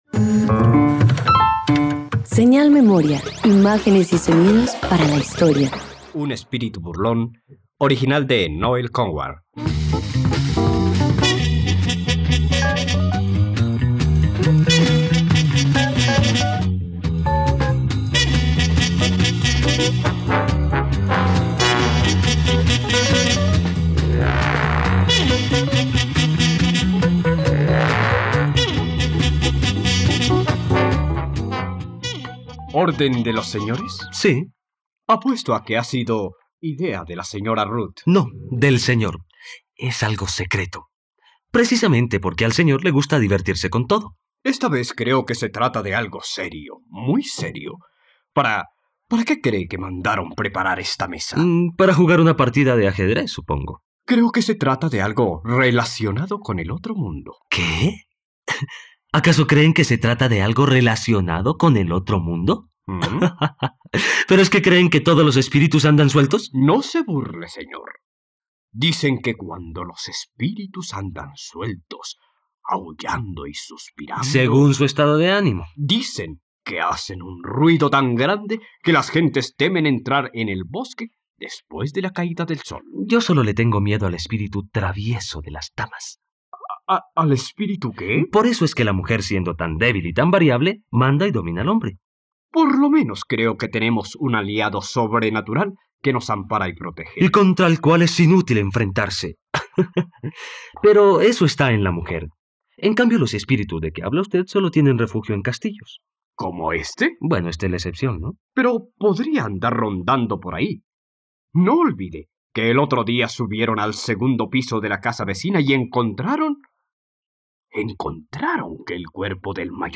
Un espíritu burlón - Radioteatro dominical | RTVCPlay
..Radioteatro. Disfruta la adaptación radiofónica de "Un espíritu burlón" del dramaturgo británico Noël Coward en la plataforma de streaming RTVCPlay.